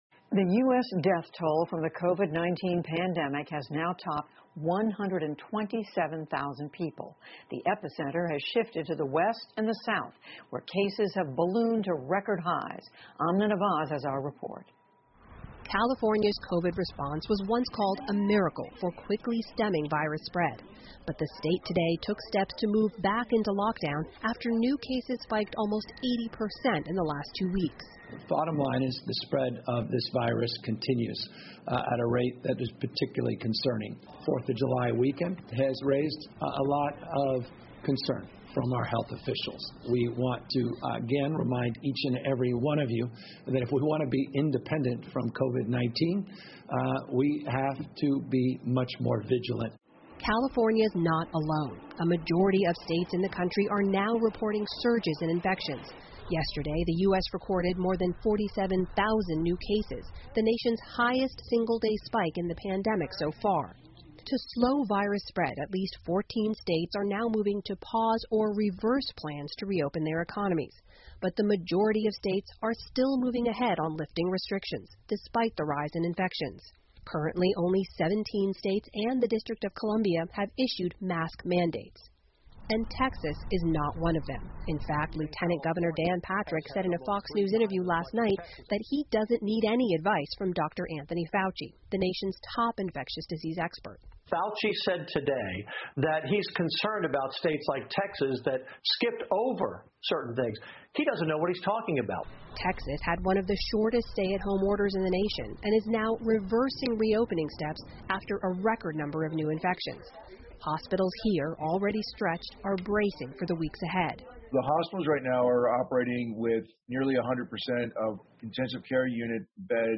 PBS高端访谈:商铺在疫情下艰难前行 听力文件下载—在线英语听力室